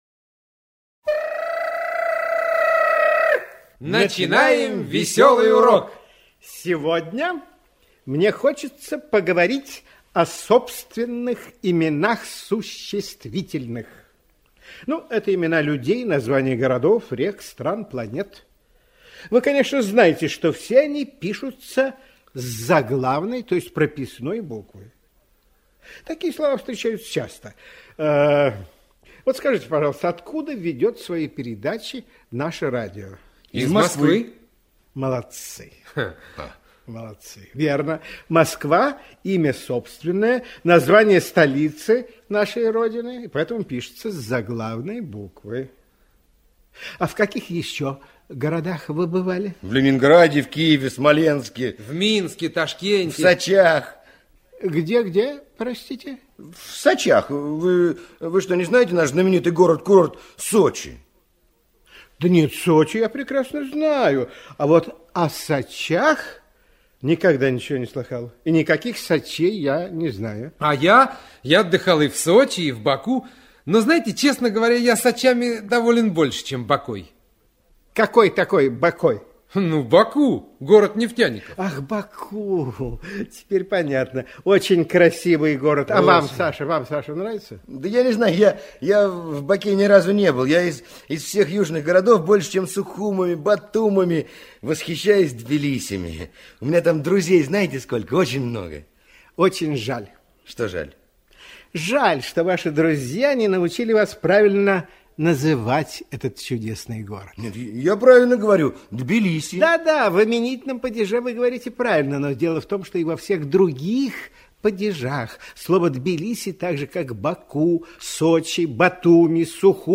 В этом разделе размещены аудиоуроки для школьников из образовательной программы «Радионяня», которая транслировалась на всесоюзном радио в 1970-1980 г.
«Весёлые уроки радионяни» в шутливой музыкальной форме помогают детям запомнить правила русского языка..